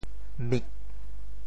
How to say the words 乜 in Teochew？
TeoChew Phonetic TeoThew mig4 白 mi1